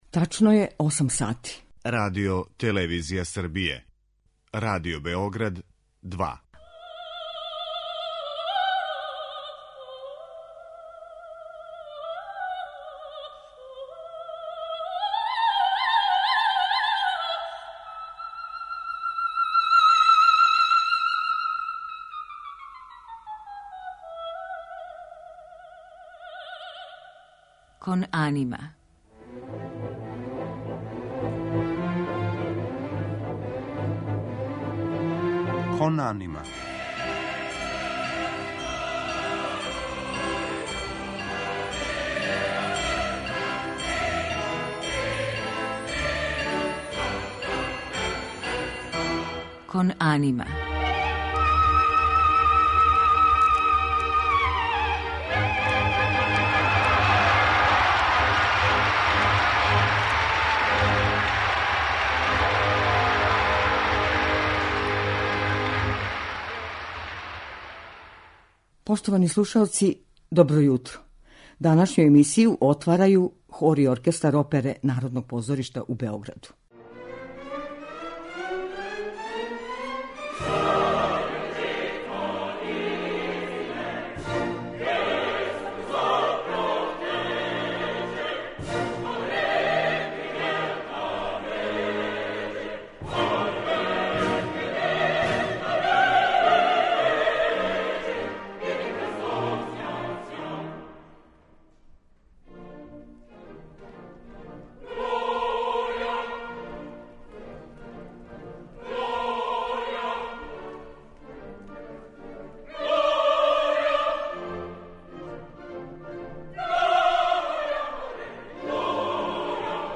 Гост је Дејан Савић, нови управник Народног позоришта у Београду.
У музичком делу емитоваћемо арије у извођењу наших еминентних оперских уметника.